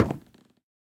1.21.5 / assets / minecraft / sounds / block / bamboo_wood / step5.ogg